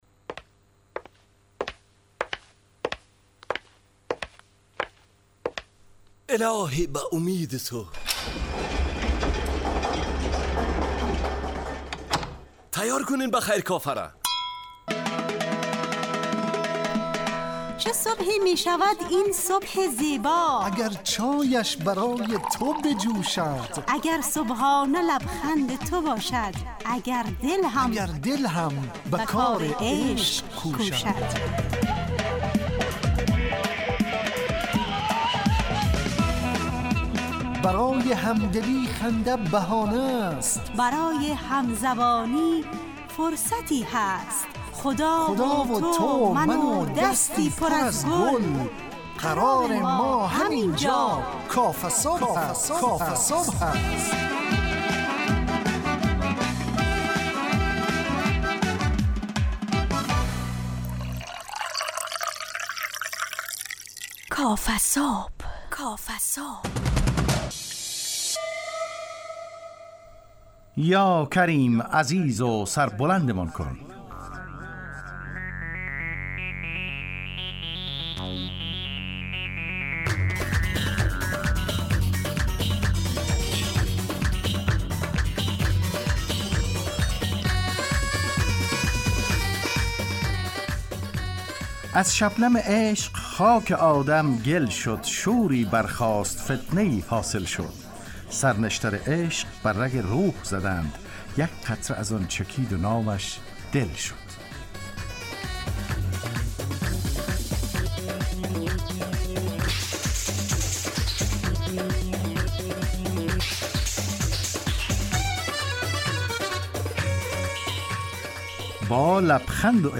کافه‌صبح – مجله‌ی صبحگاهی رادیو دری با هدف ایجاد فضای شاد و پرنشاط صبحگاهی همراه با طرح موضوعات اجتماعی، فرهنگی و اقتصادی جامعه افغانستان با بخش‌های کارشناسی، نگاهی به سایت‌ها، گزارش، هواشناسی، صبح جامعه و صداها و پیام‌ها شنونده‌های عزیز